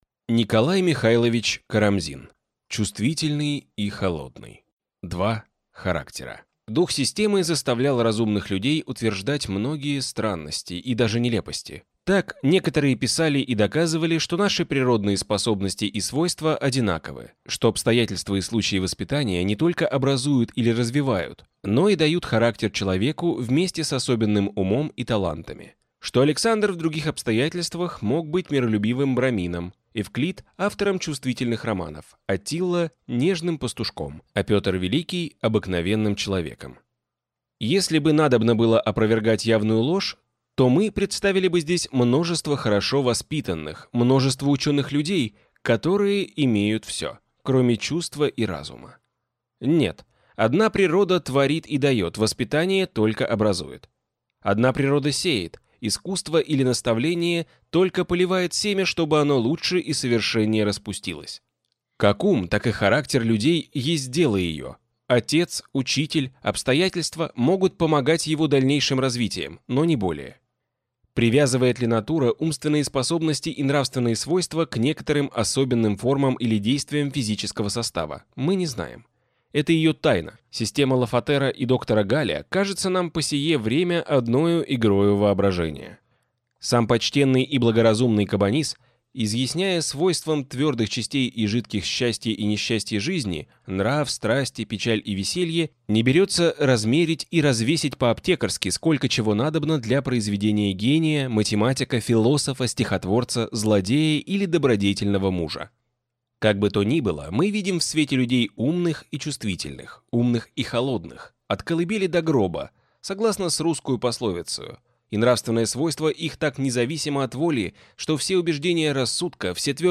Аудиокнига Чувствительный и холодный | Библиотека аудиокниг